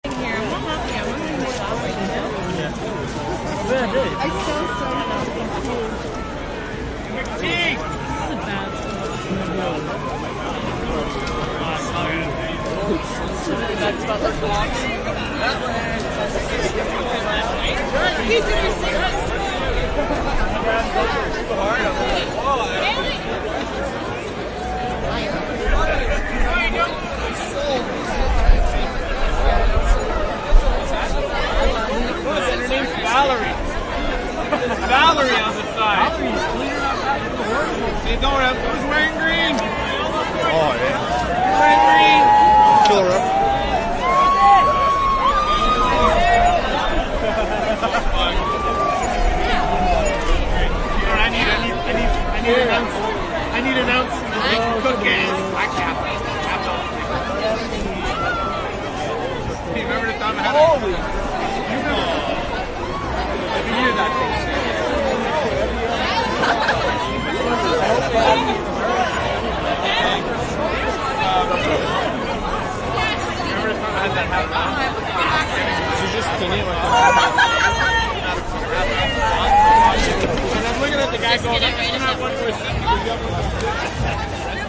蒙特利尔 " 人群中的游行队伍，在人群中忙碌的年轻人 圣帕特里克节 蒙特利尔，加拿大
描述：人群分机游行忙于人群年轻人圣帕特里克节蒙特利尔，Canada.flac
标签： 分机 年轻 人群 游行
声道立体声